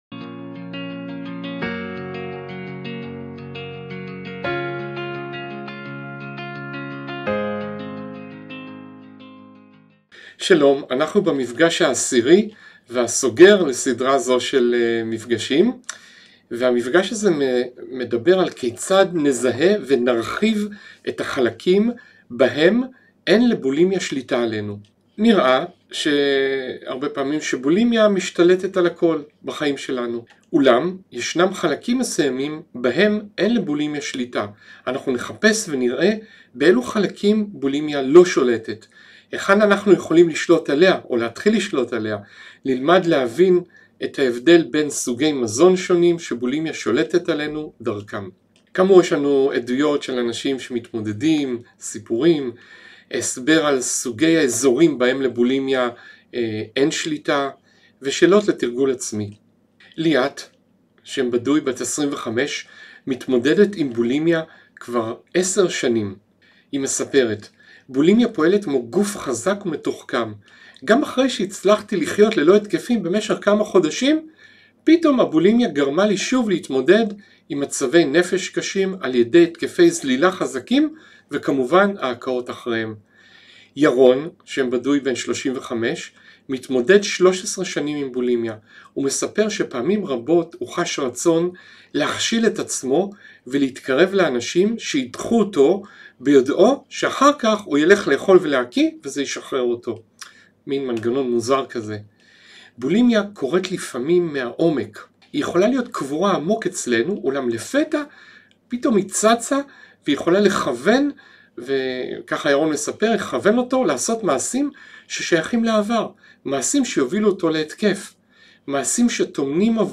פסיכולוג קליני מומחה